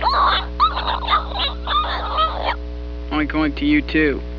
squeal.wav